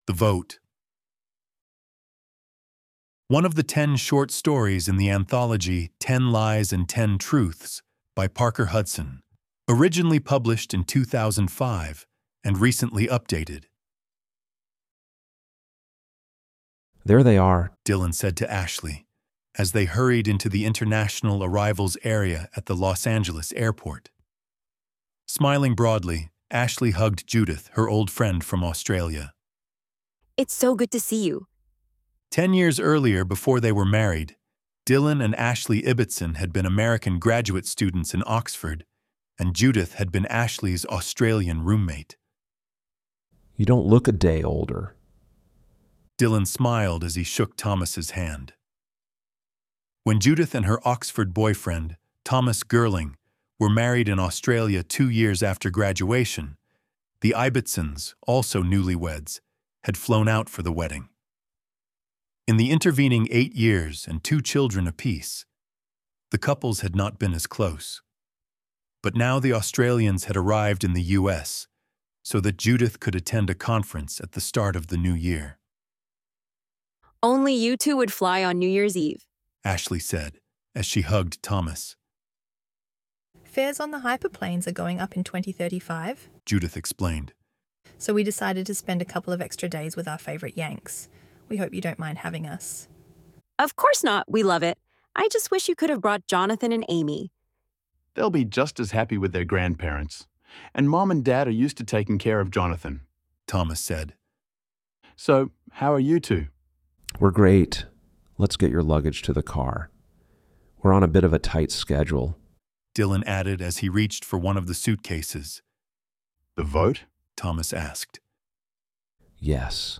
ElevenLabs_The_Vote_Eleven_Labs_Test.docx.mp3